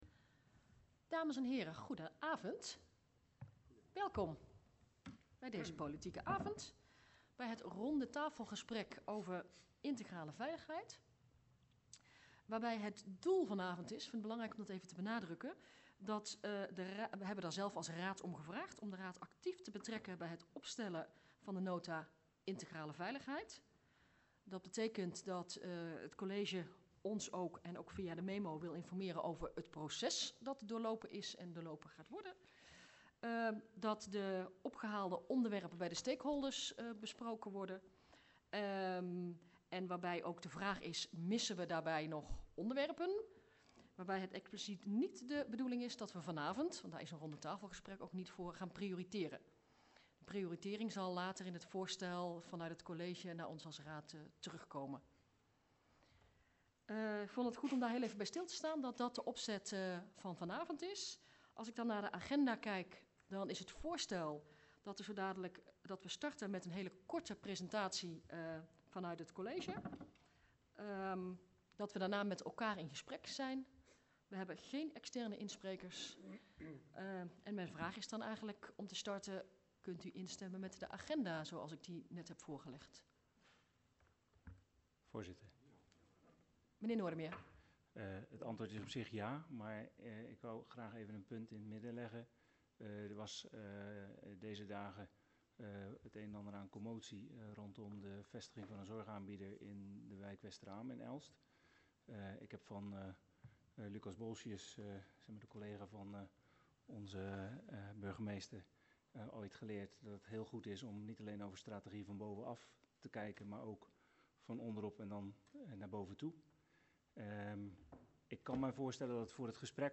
VMBO Het Westeraam Elst, tijdelijke raadzaal